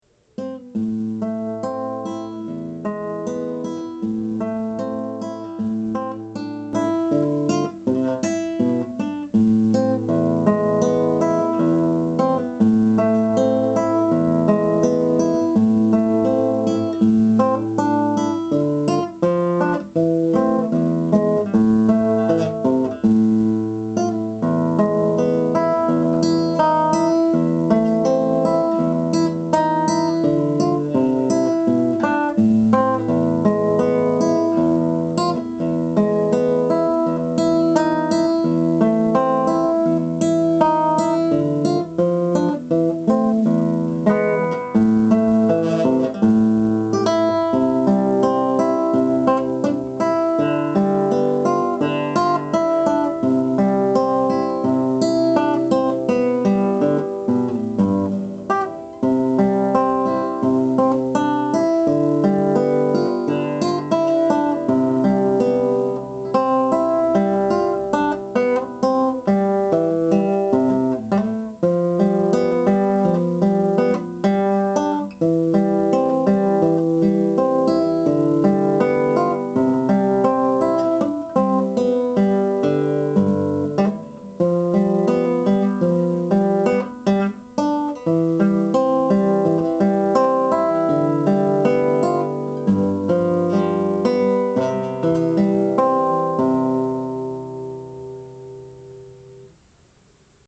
It’s a bit lighter in color, but a much heavier wood- made of palo-escrito, or “Mexican Rosewood”. It has a richer tone, and plays a lot louder than my first guitar.
song on the new guitar.
There are mistakes in both of them, and they represent the best of ~9-10 takes (each), but should be enough so you can hear the difference between the two guitars, and see the process that someone has to go through in order to entertain you with even the simplest of songs.